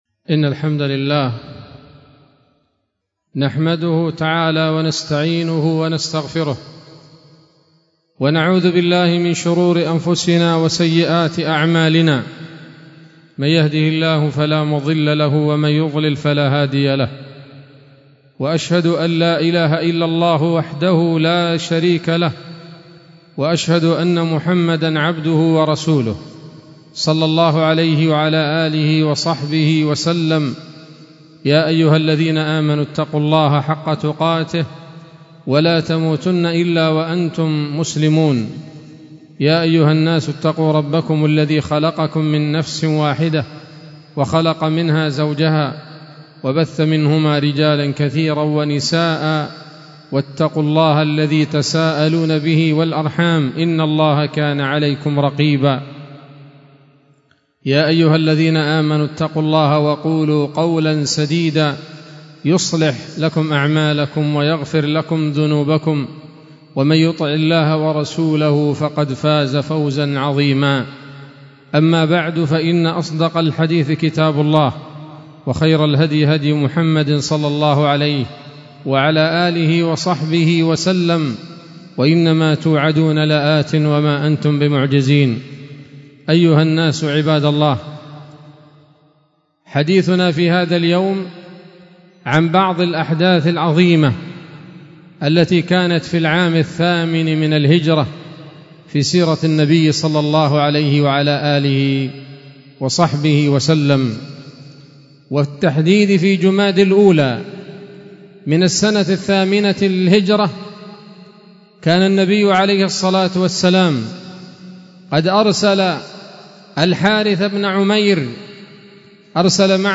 خطبة جمعة بعنوان: (( السيرة النبوية [28] )) 27 محرم 1446 هـ، دار الحديث السلفية بصلاح الدين